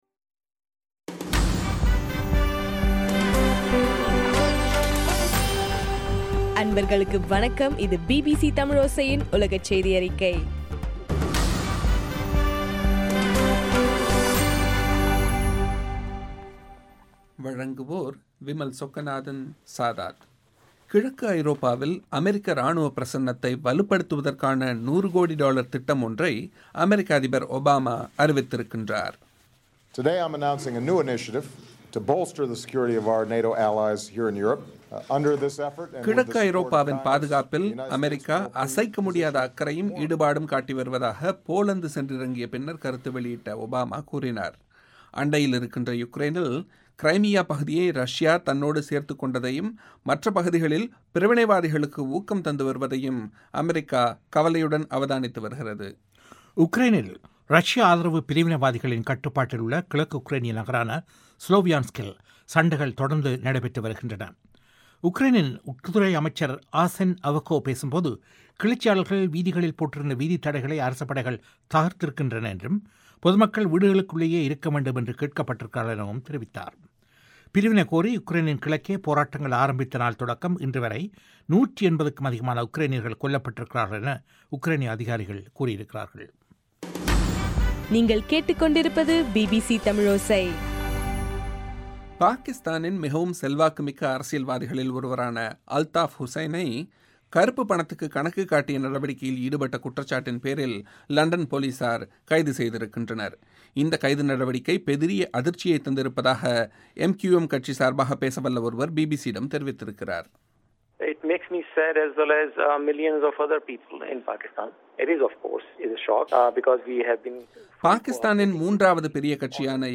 ஜூன் 3 பிபிசியின் உலகச் செய்திகள்